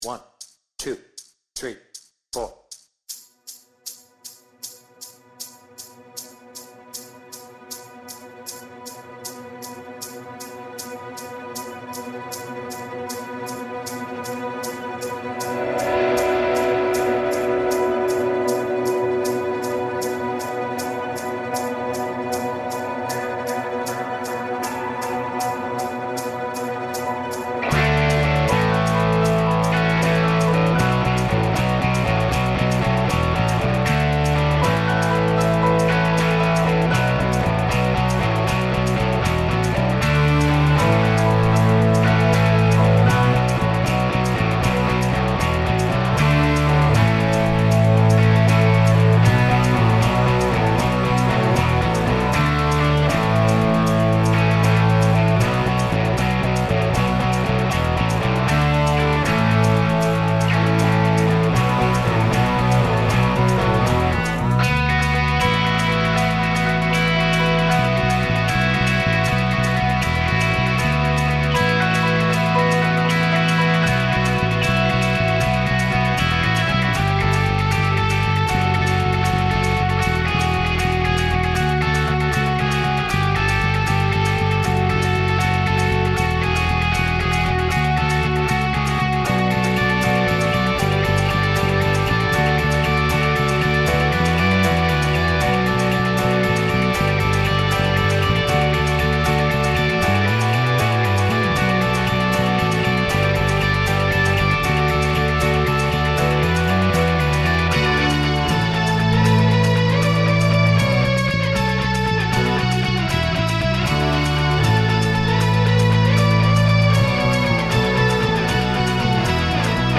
Without vocals
Based on 360° Tour and album